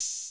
Closed Hats
Metro HI-HAT 10.WAV